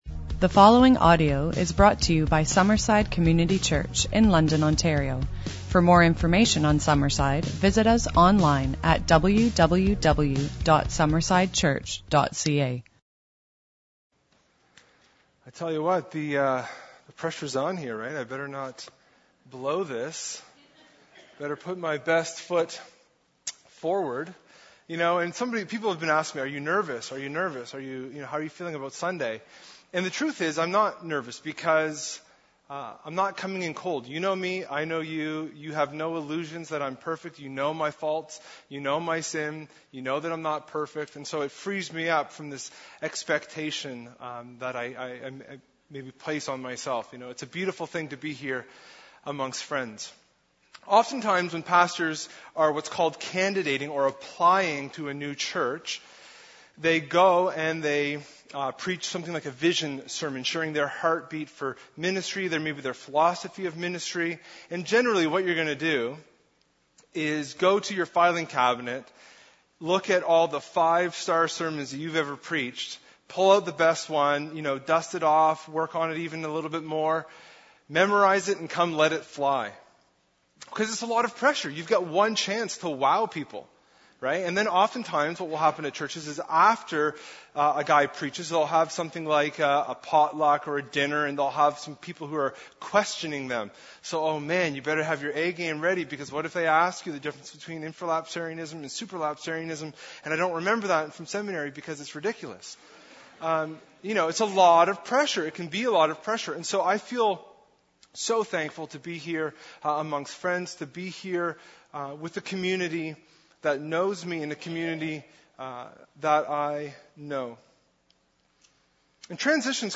Transition Service and vote. …